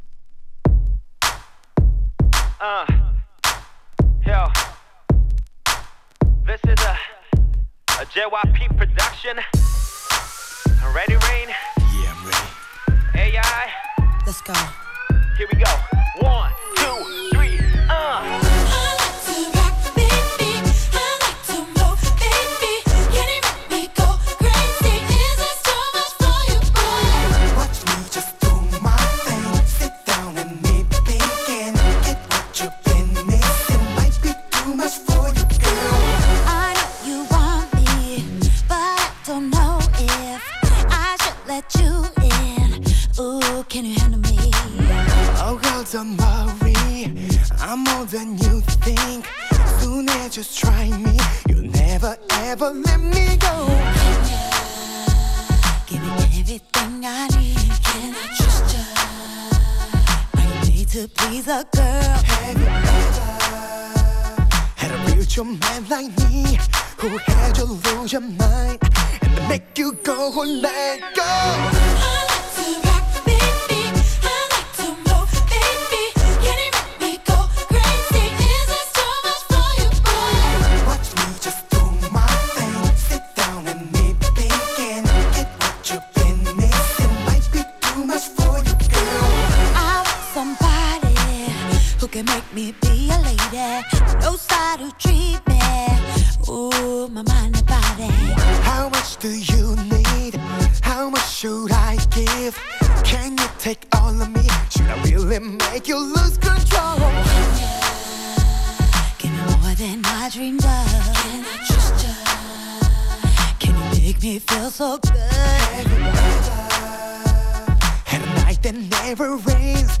2. > R&B